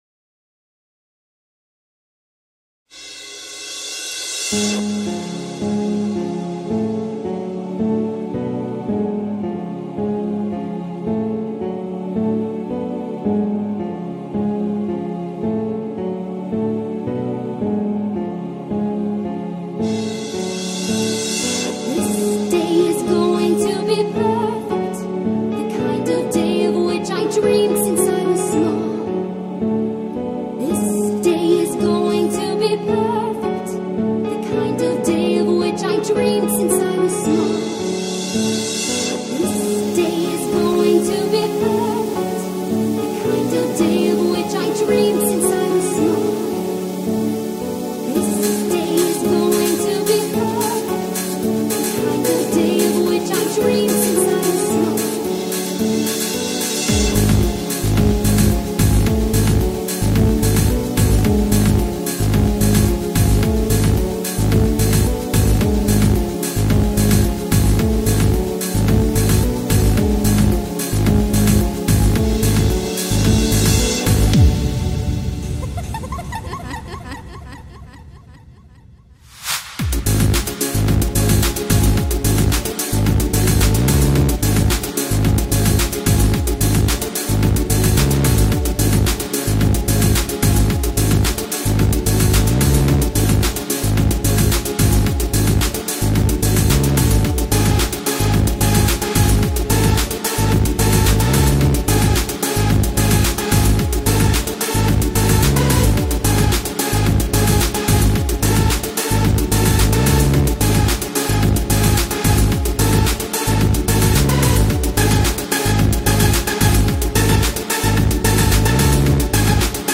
Welp I couldn't Help myself I had to remix this song.